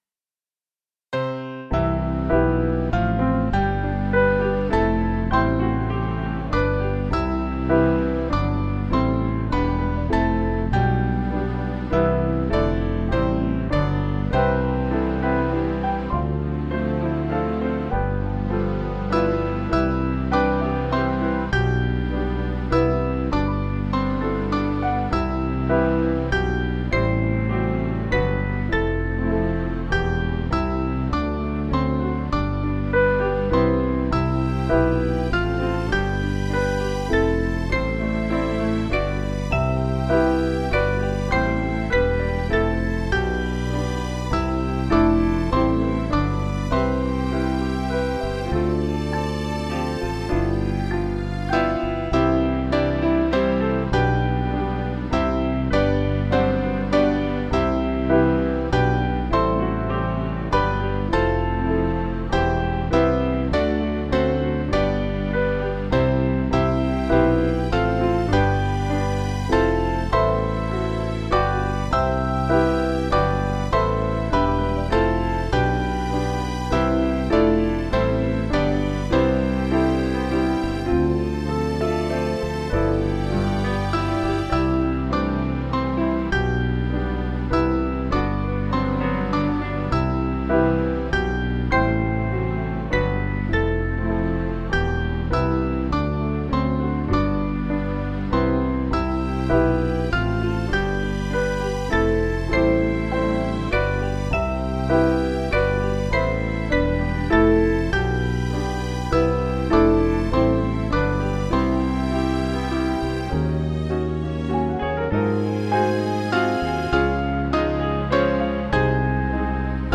Mainly Piano